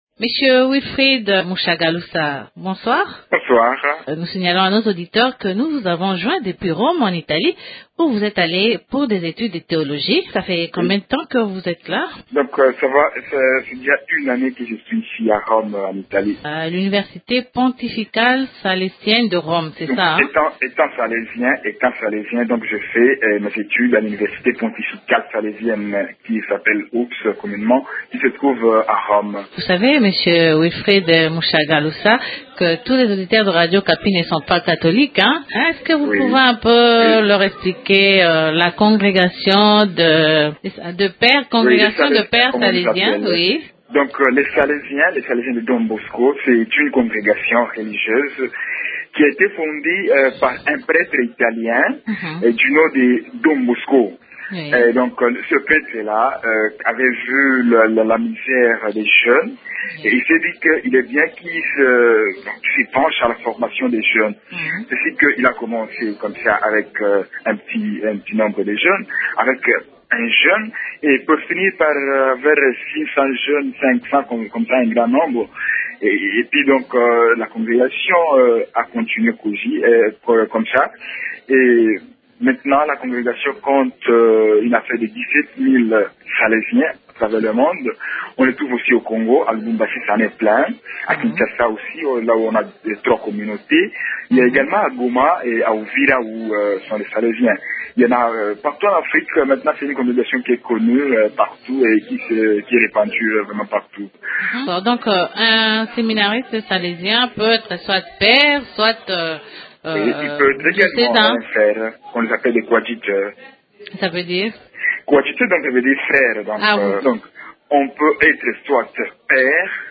Il est joint au téléphone depuis l’Italie